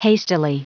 Prononciation du mot : hastily